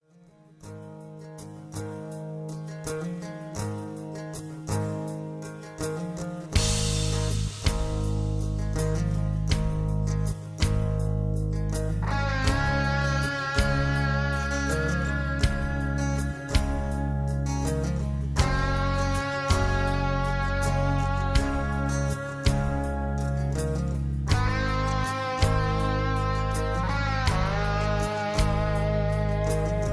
karaoke collection , backing tracks